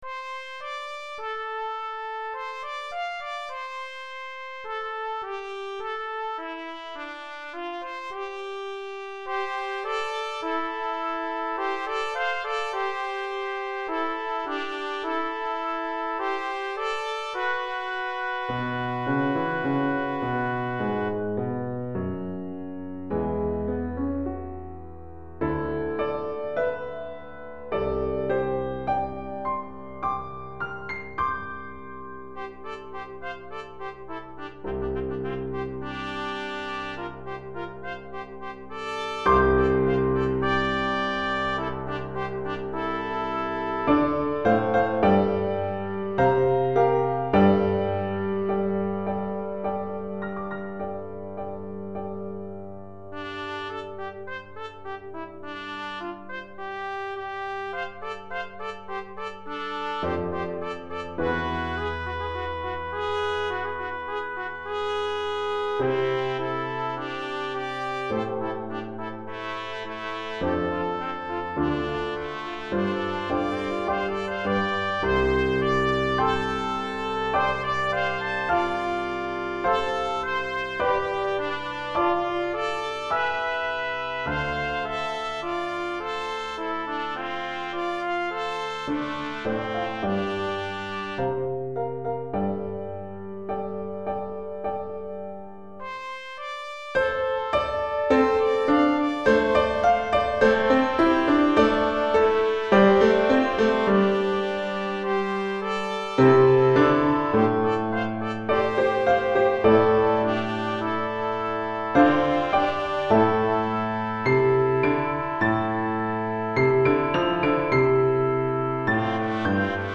Pour 2 trompettes ou cornets et piano DEGRE CYCLE 1